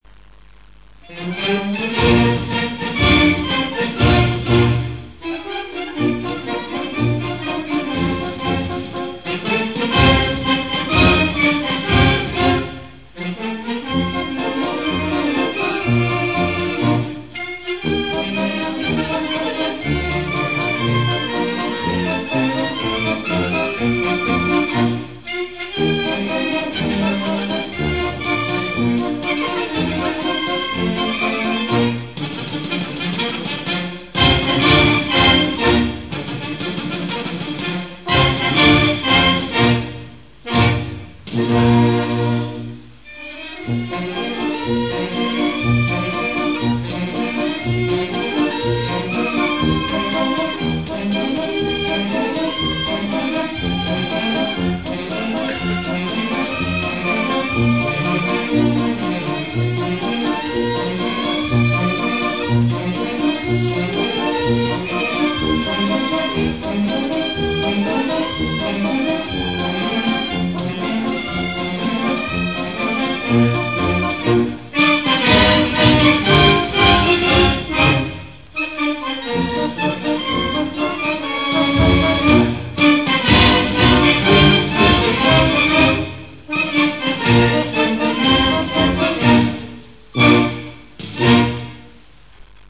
Puis, entre 1870 et 1880, un certain Candeias, de la Bande Militaire de Ponta Delgada a eu la bonne idée de composer une hymne en l’honneur du Saint Christ ; une marche un peu trop militaire, mais qui est encore jouée de nos jours.
Hymne. (MP3)